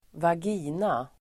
Uttal: [²vag'i:na]